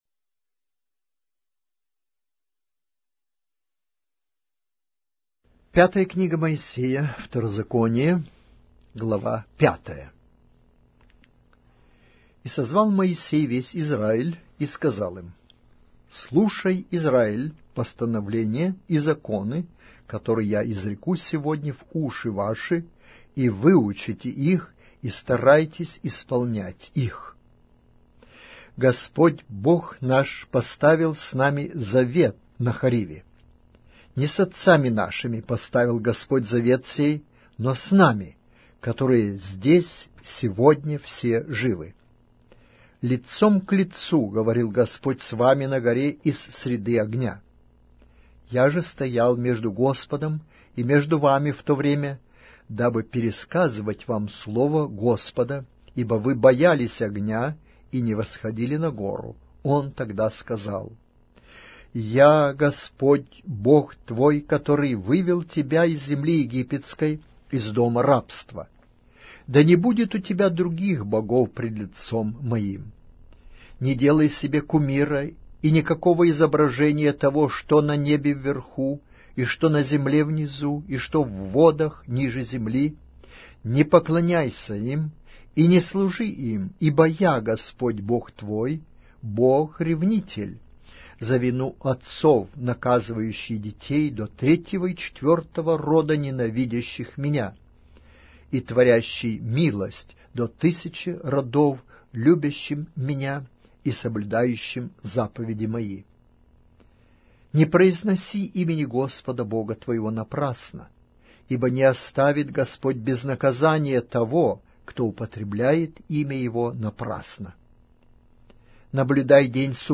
Аудиокнига: Книга 5-я Моисея. Второзаконие